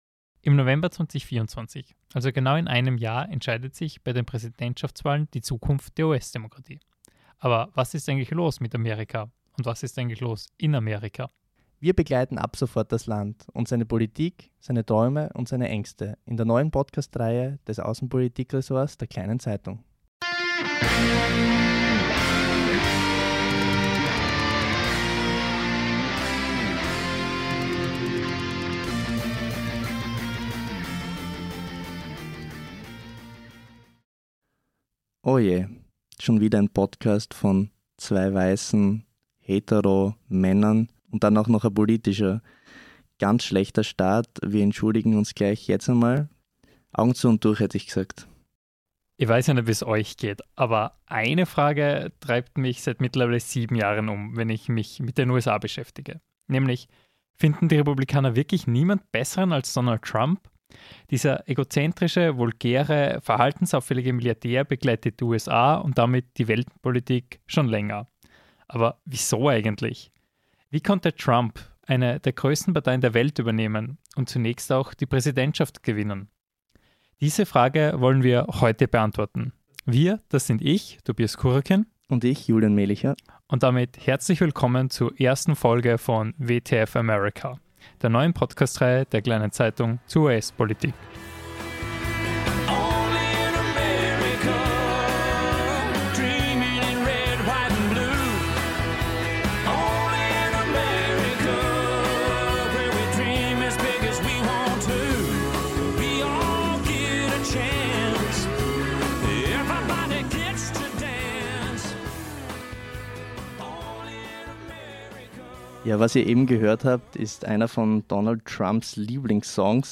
Darüber, warum das trotz – oder wegen – der unzähligen Prozesse möglich ist und was das für den Ausgang der Wahl bedeuten könnte, sprechen wir in dieser Folge. Zu Gast: Politologe